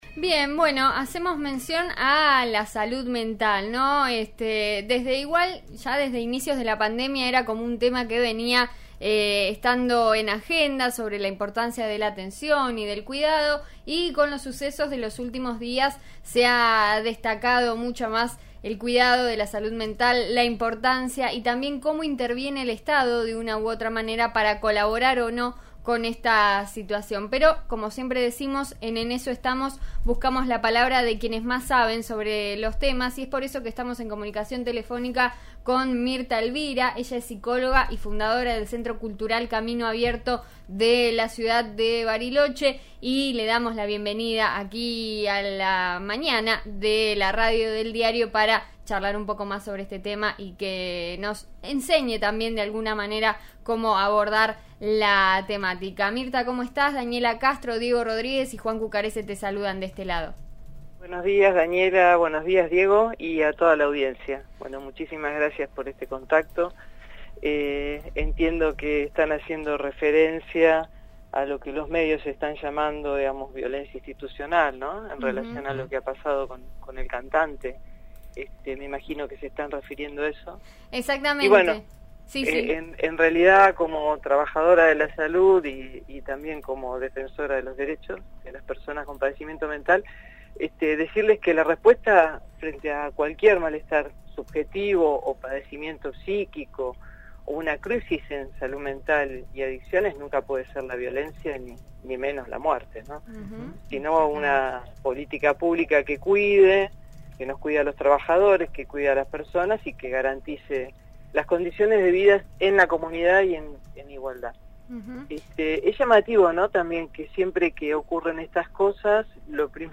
dialogó con En Eso Estamos por Río Negro Radio.